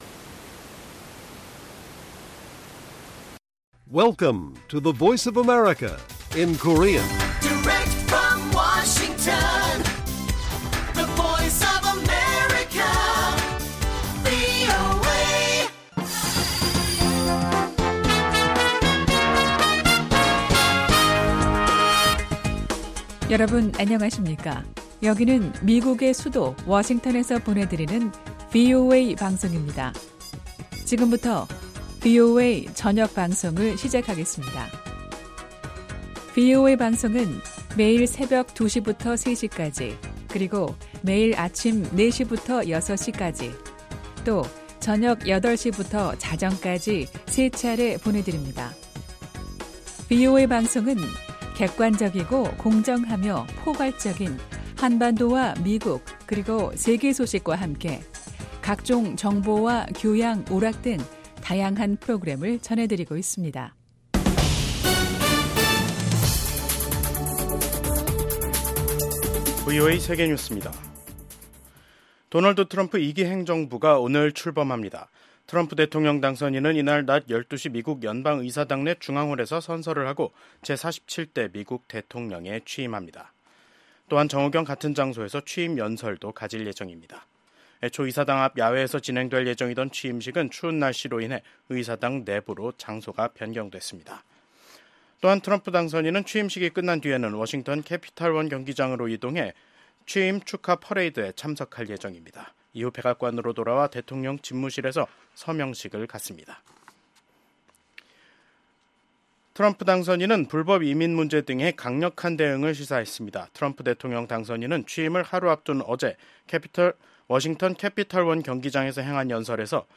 VOA 한국어 간판 뉴스 프로그램 '뉴스 투데이', 2025년 1월 20일 1부 방송입니다. 미국의 제47대 도널드 트럼프 대통령의 취임식 날입니다. VOA한국어 방송의 뉴스투데이는 오늘, 취임식 특집 방송으로 진행합니다.